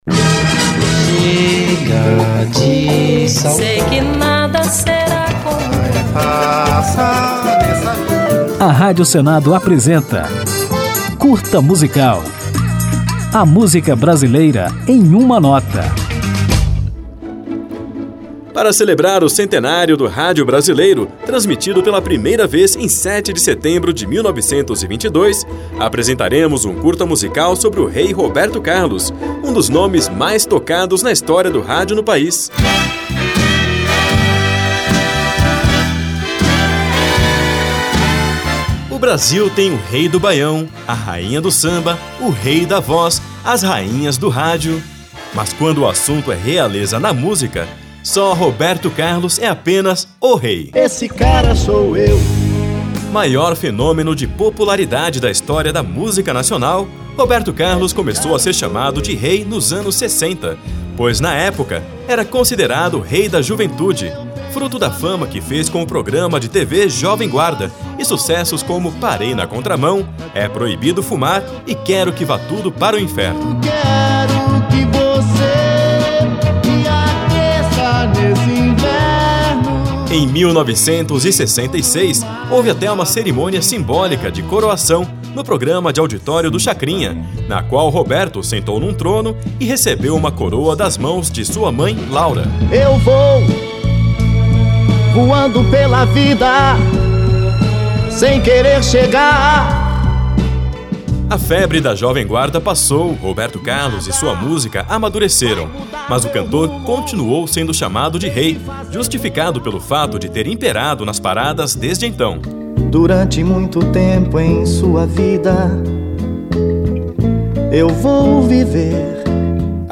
Para coroar esta edição, ouviremos o Rei na música Emoções.